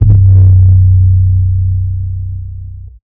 FOR THE LAST TIME 808.wav